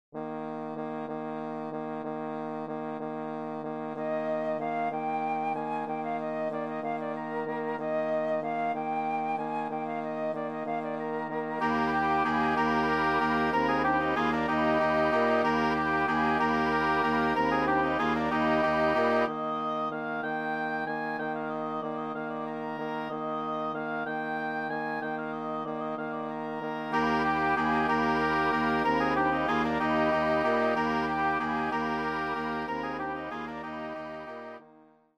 Kolędy Znaczniki